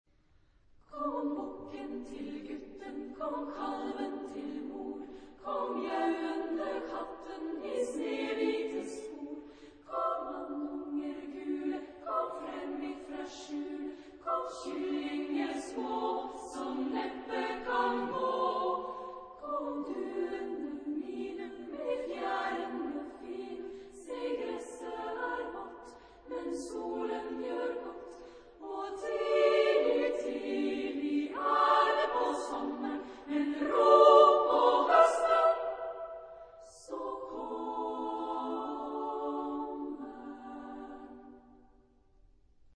Genre-Style-Form: Popular ; Lied
Mood of the piece: fast
Type of Choir: SSA  (3 women voices )
Tonality: G major